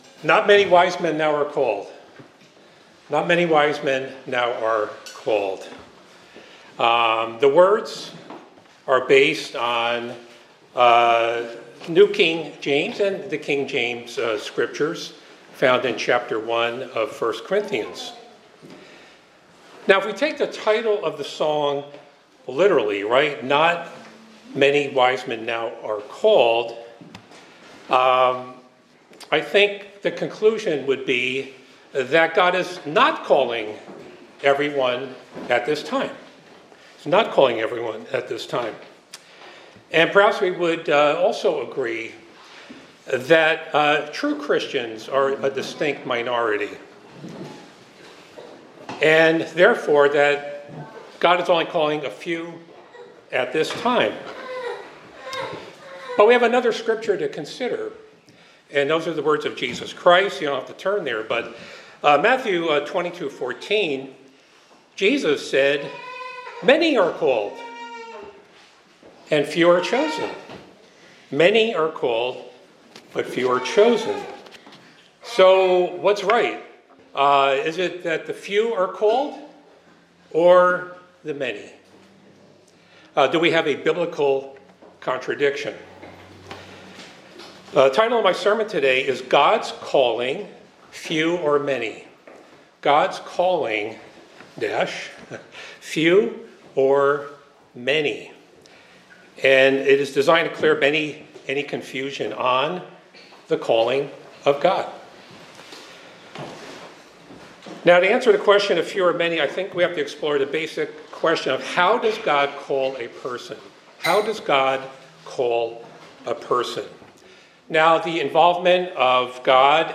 This sermon discusses the concept of God's calling, whether it is extended to few or many people. It explores biblical scriptures to clarify how God calls individuals.
Given in Hartford, CT